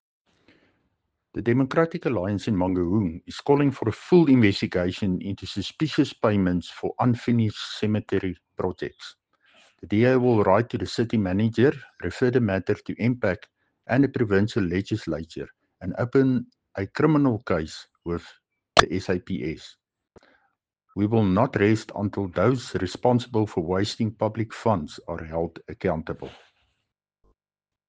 English and Afrikaans soundbites by Cllr Dirk Kotze and Sesotho soundbite by Cllr Kabelo Moreeng.